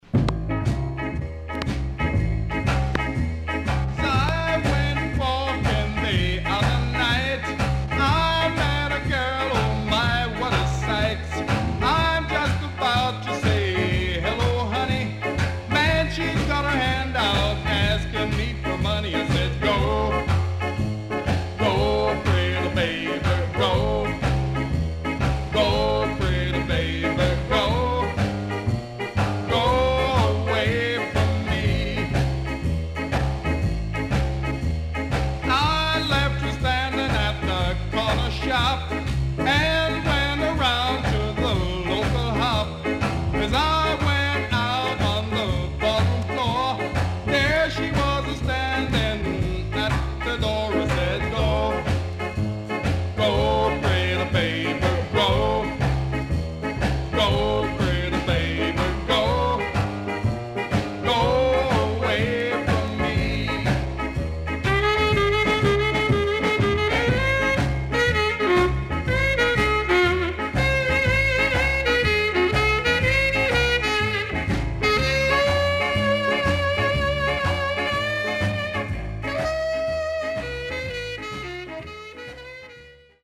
HOME > Back Order [VINTAGE 7inch]  >  SKA  >  SHUFFLE
SIDE A:盤質は良好です。